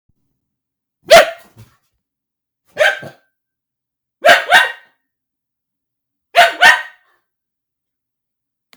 Dog Bark Sound Mp3 Sound Button - Free Download & Play
Dog Sounds752 views